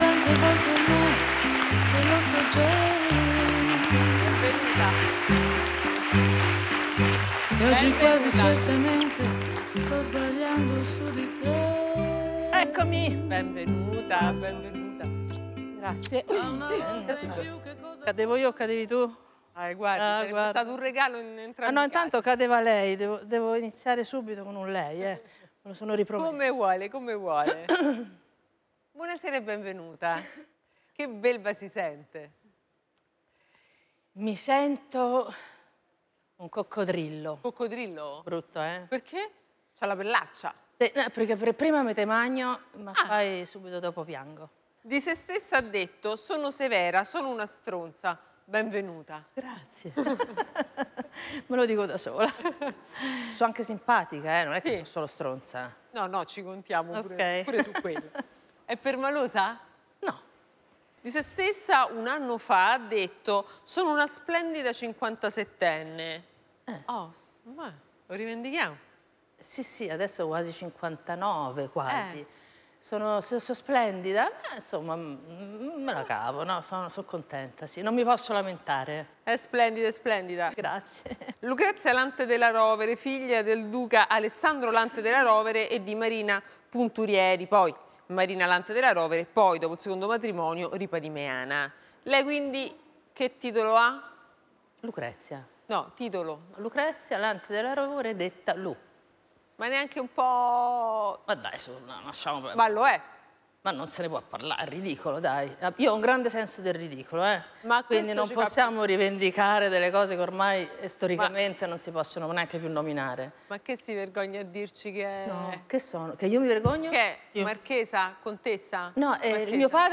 Francesca Fagnani si confronta senza sconti con grandi nomi dello spettacolo, della politica, del costume e della cronaca, disposti a mettersi davvero in gioco, accettando le regole del programma: raccontarsi senza filtri, rispondere alle domande chiare, dirette e spesso irriverenti della conduttrice.